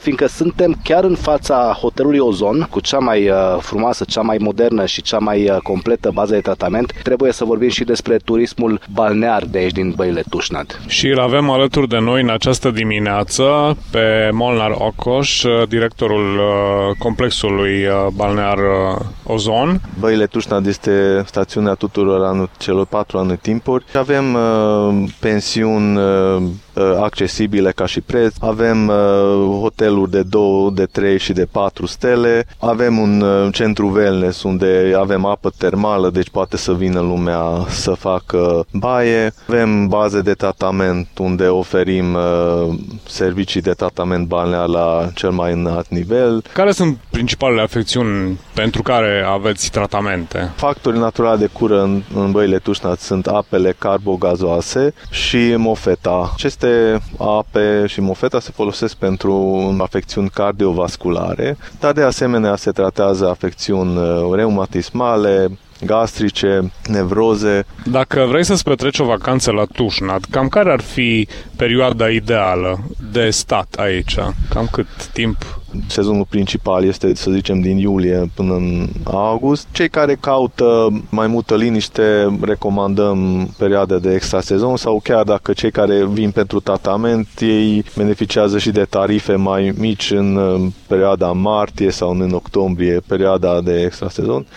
în drum spre Lunca de Sus